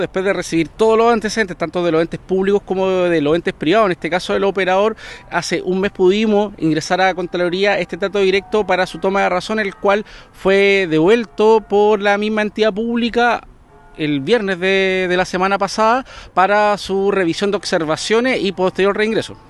Consultado por Radio Bío Bío, el seremi de Transportes de Los Ríos, Jean Pierre Ugarte, confirmó que este es un contrato mediante “trato directo” que debe ser revisado por la Contraloría y los antecedentes fueron enviados los primeros días de julio, pero fue devuelto con observaciones.